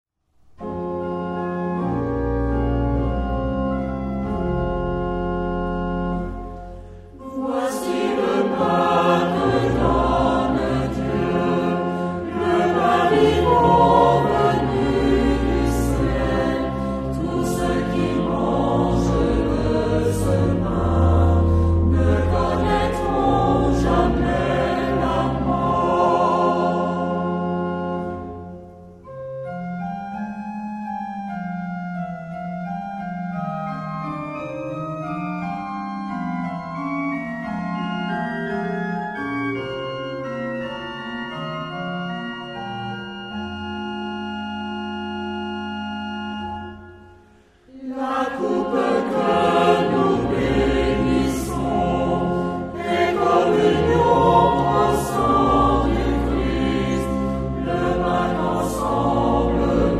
Genre-Style-Forme : Sacré ; Procession
Caractère de la pièce : vivant
Type de choeur : SATB  (4 voix mixtes )
Instruments : Orgue (1)
Tonalité : fa dièse mineur